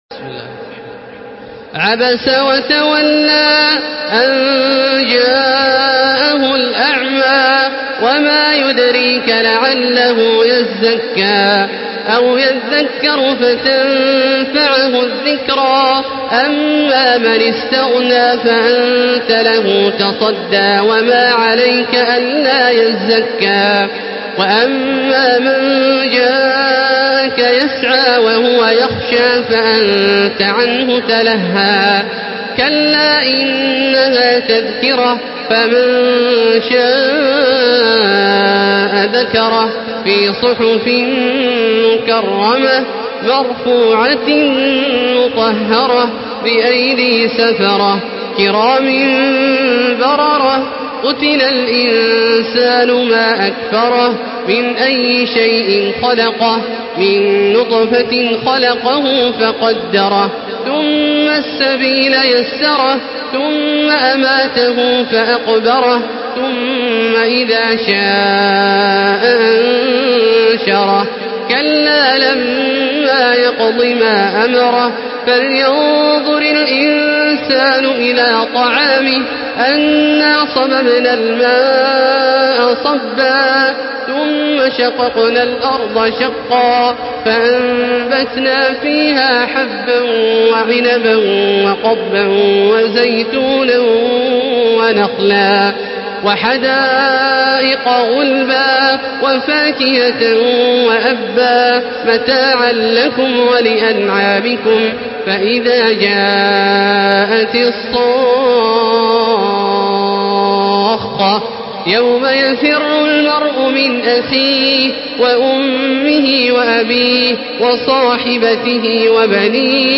تحميل سورة عبس بصوت تراويح الحرم المكي 1435
مرتل حفص عن عاصم